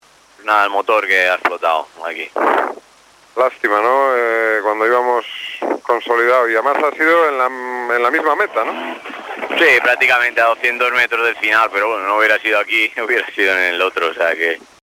Declaraciones de los pilotos, cortesía de Cadena Dial: